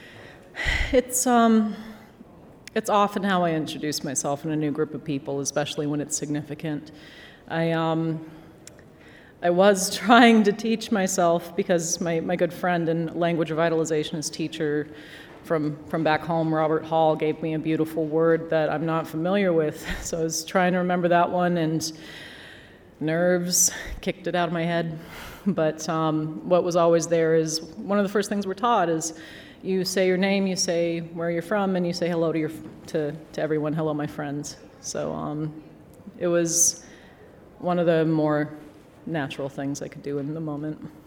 Gladstone talked about that instance during her backstage interviews (which you can listen to below).